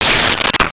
Space Gun 01
Space Gun 01.wav